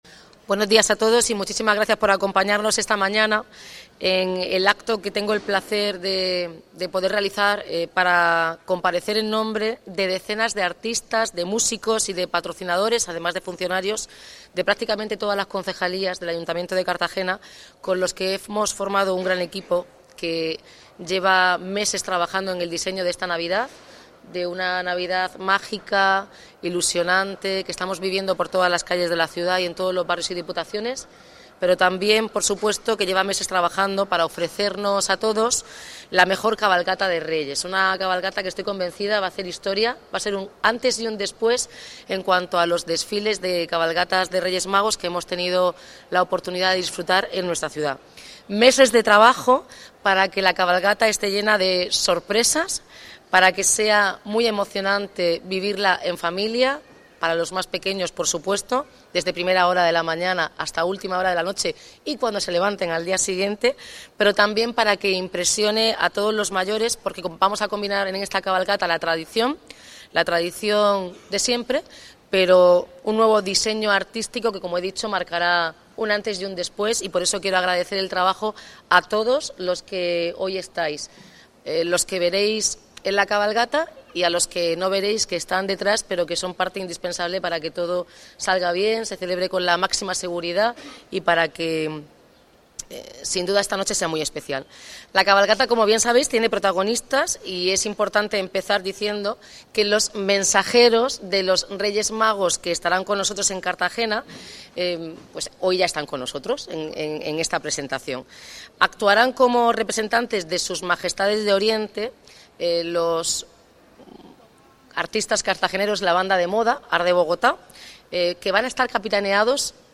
Enlace a Declaraciones de la alcaldesa, Noelia Arroyo, sobre la Cabalgata de Reyes